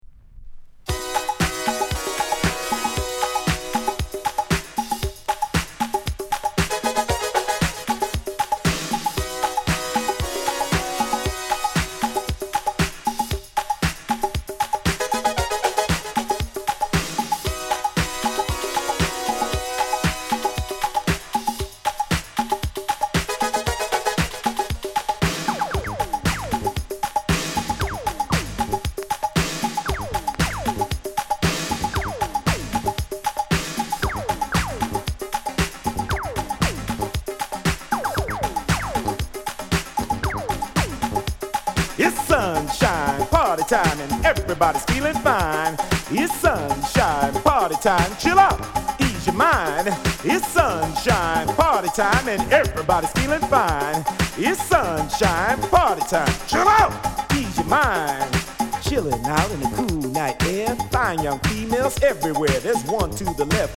Sound Condition VG
B side - light off center Coment ISLAND ELECTRO